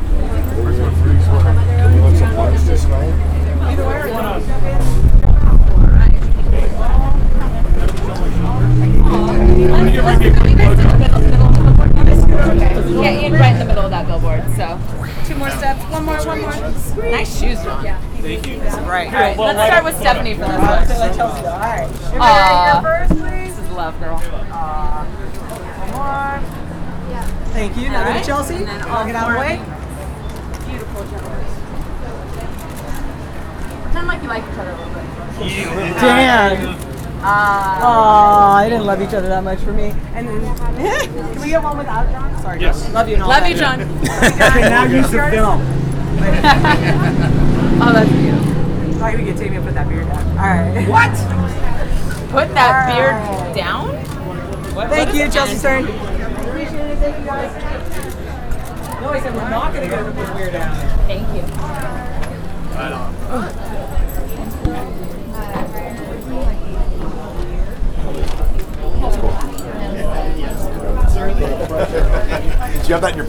RANDOM PRESS CONFERENCE AUDIO